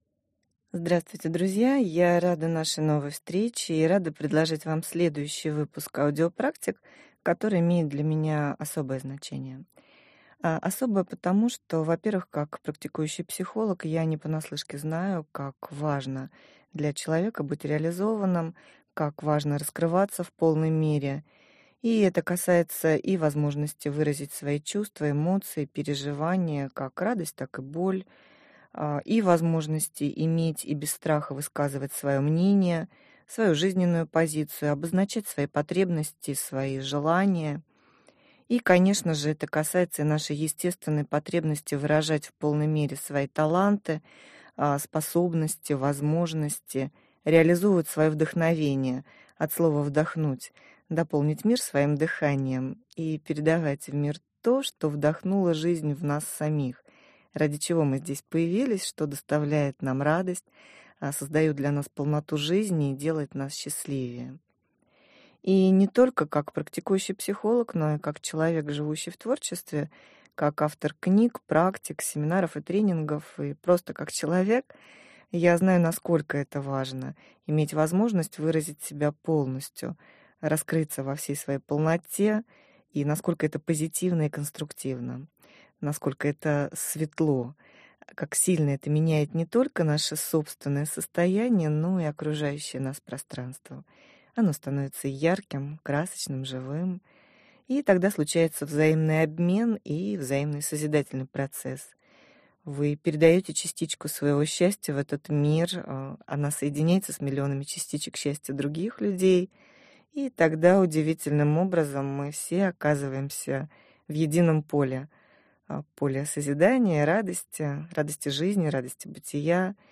Аудиокнига Я выражаю себя!!! Практики для самораскрытия | Библиотека аудиокниг